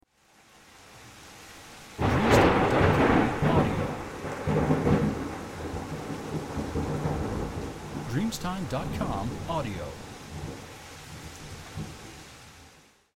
Suono 01 di tuono e del fulmine
• SFX